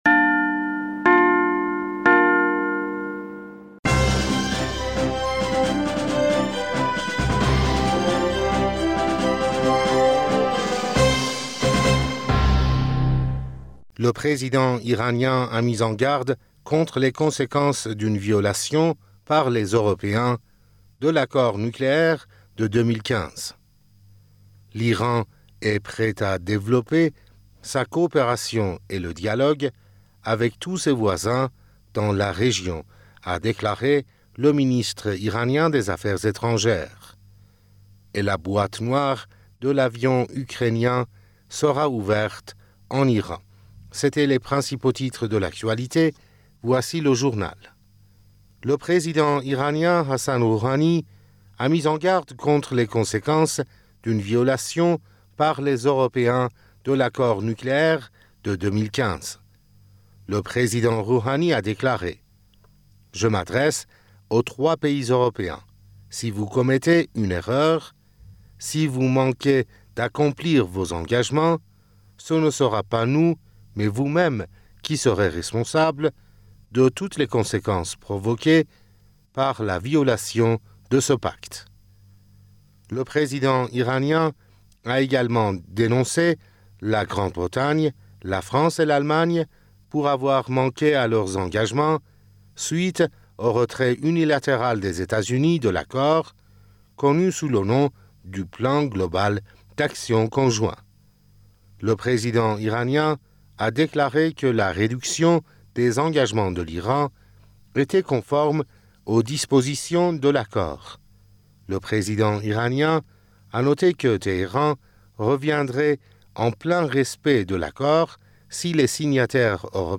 Bulletin d'information du 23 janvier 2020